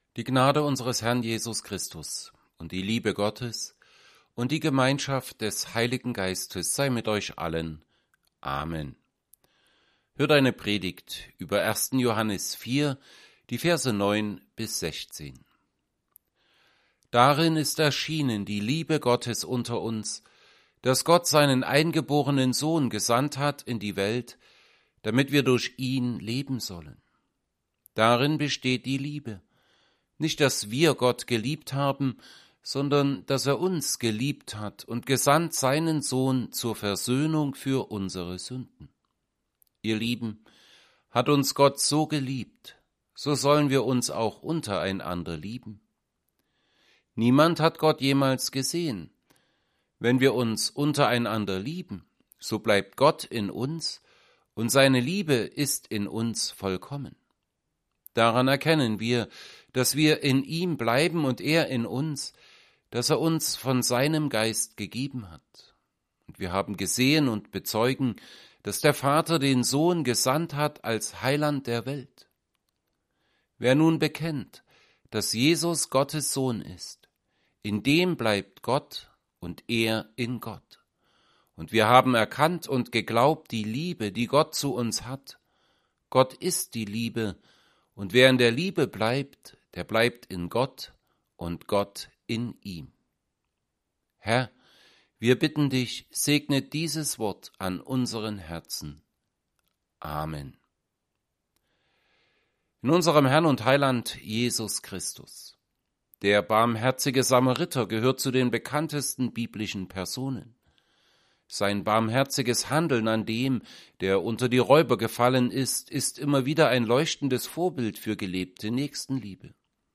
Predigt_zu_1Johannes_4_9b16.mp3